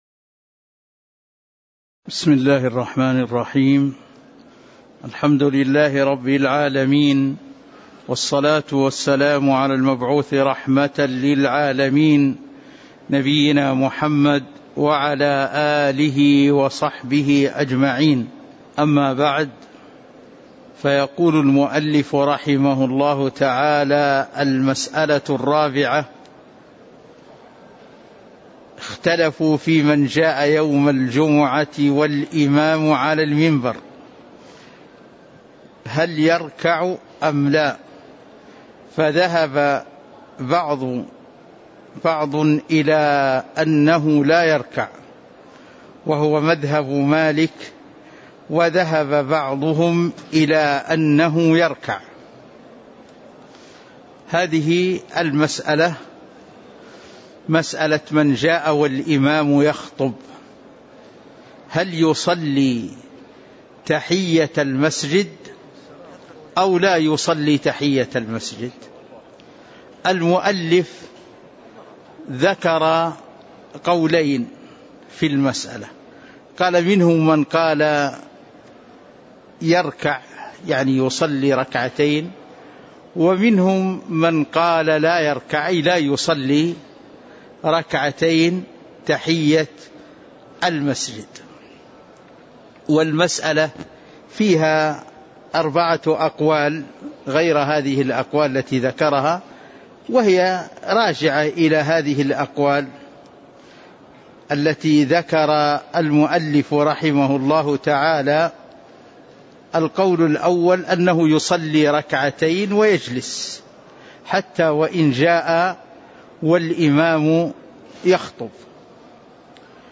تاريخ النشر ٦ جمادى الآخرة ١٤٤٣ هـ المكان: المسجد النبوي الشيخ